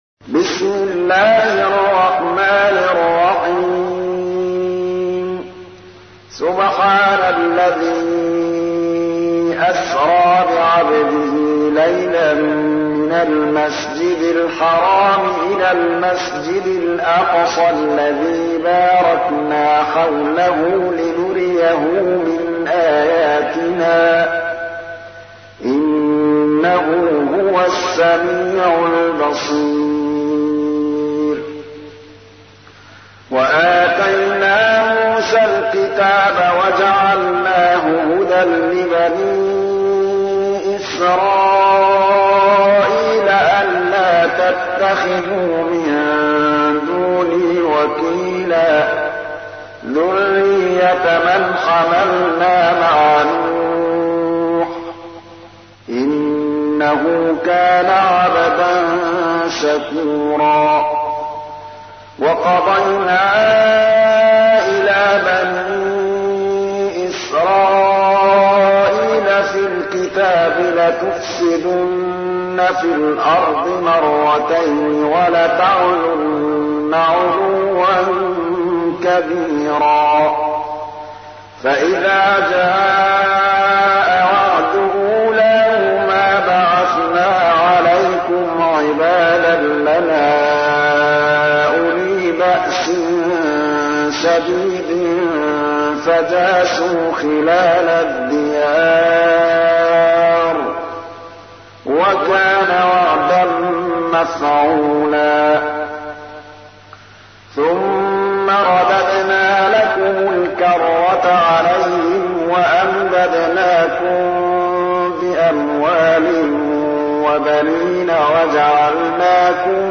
تحميل : 17. سورة الإسراء / القارئ محمود الطبلاوي / القرآن الكريم / موقع يا حسين